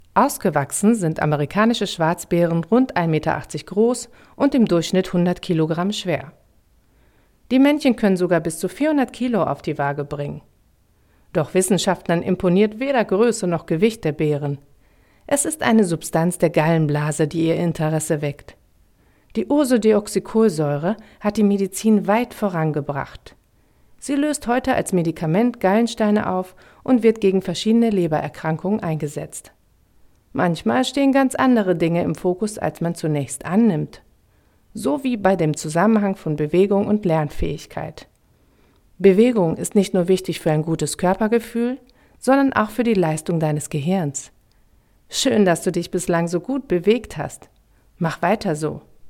Sprechproben
sachlich/ motivierend – App-Inhalt
Sprechprobe-sachlich-motivierend.mp3